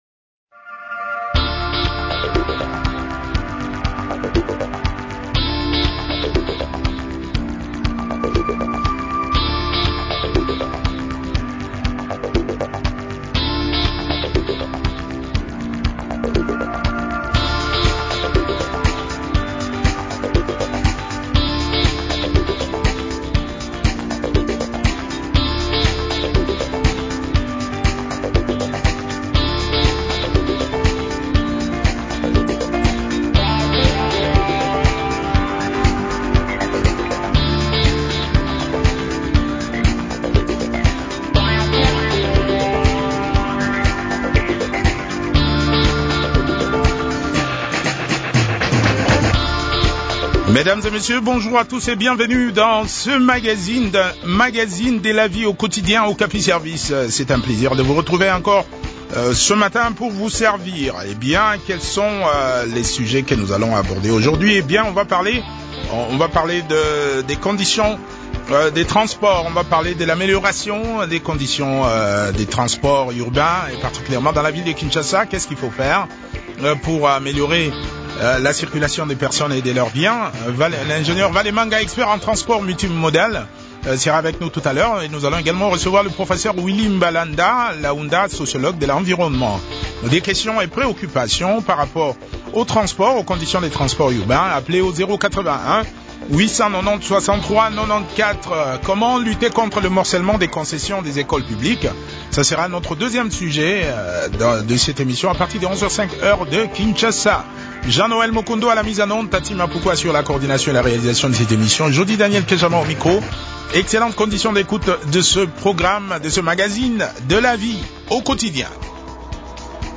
expert en transport multimodal.
sociologue de l’environnement a également pris part à cette interview.